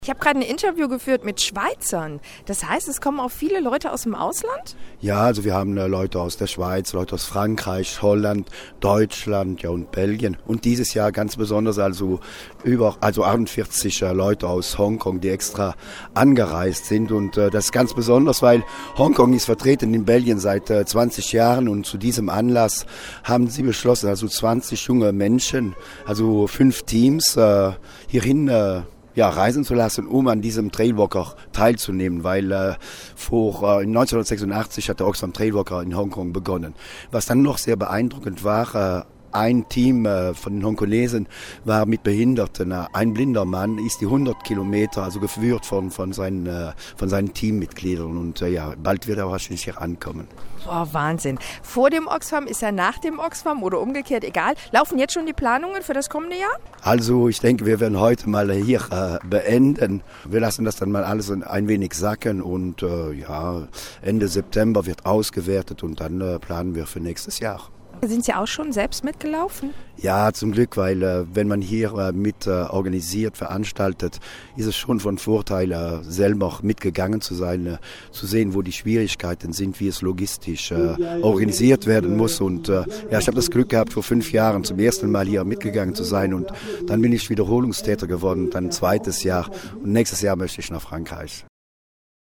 Eindrücke von der Oxfam Trailwalker Zielankunft